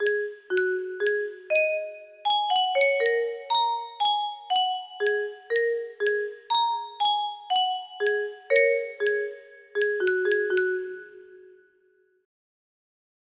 Unknown Artist - Untitledasdasdasdasd music box melody